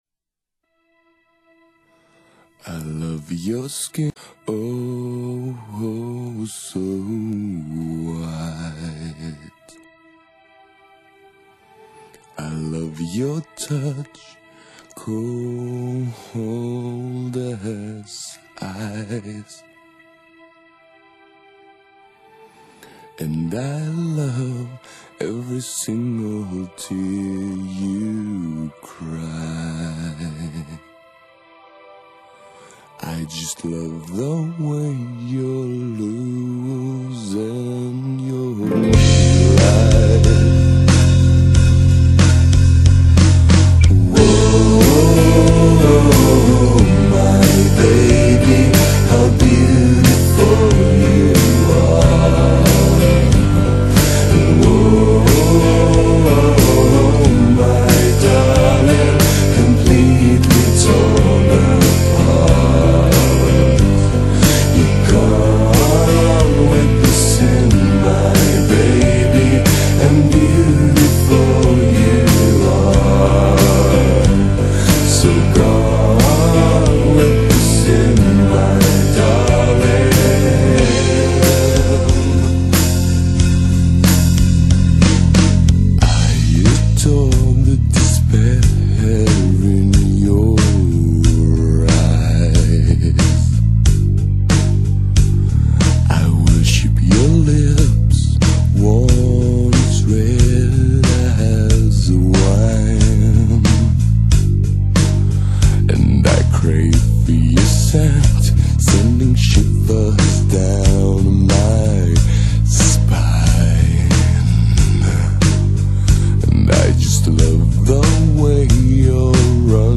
love metal